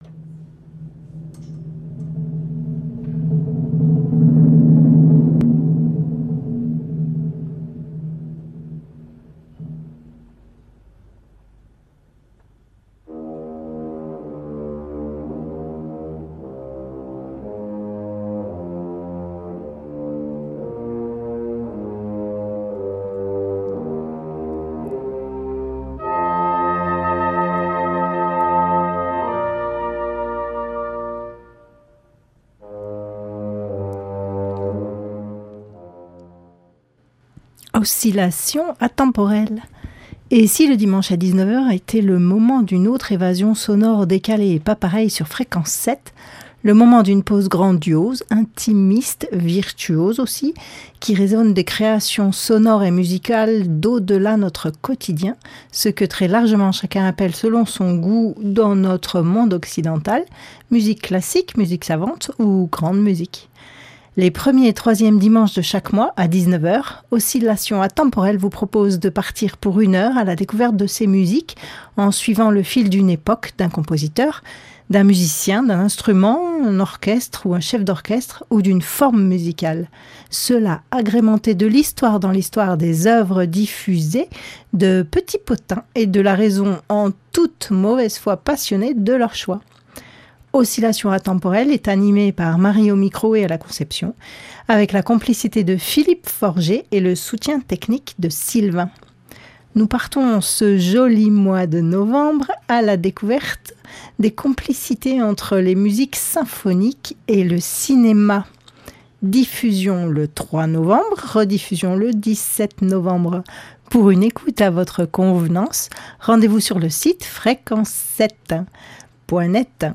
Hybrides et Classiques familiers